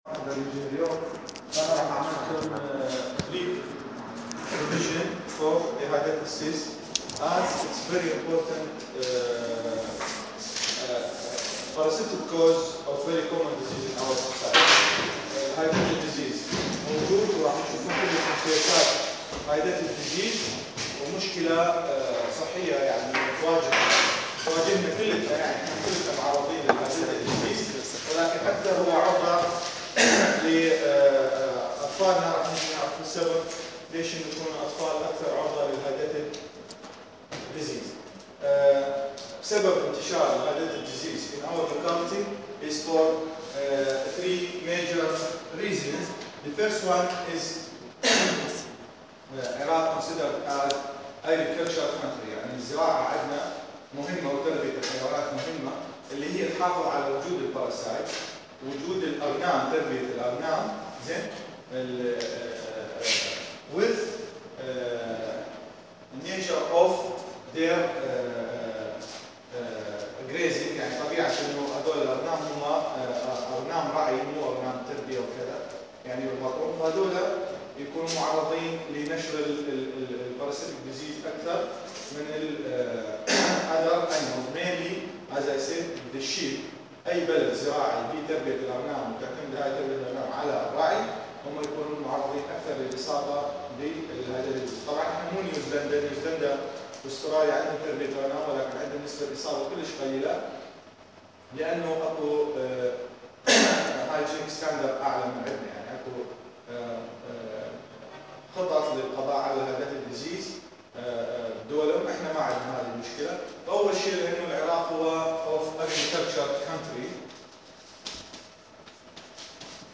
الطفيليات > محاضرة رقم 10 بتاريخ 2014-03-19